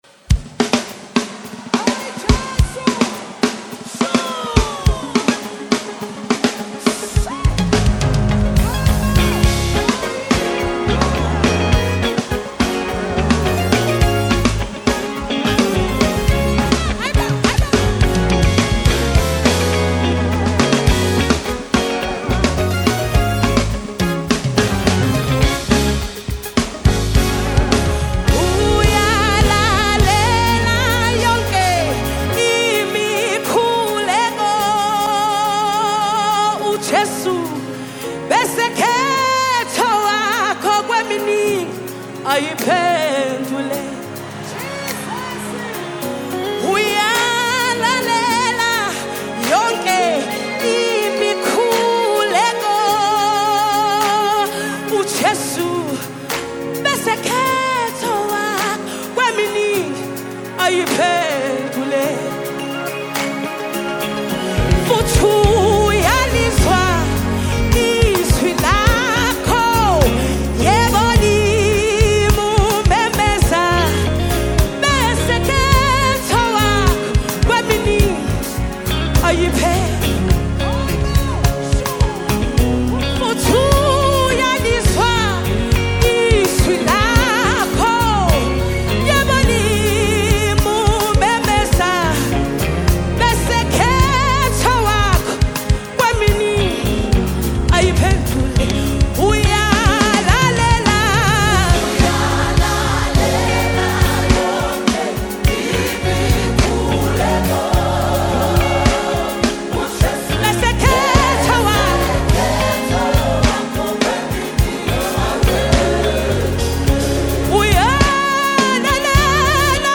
powerful worship song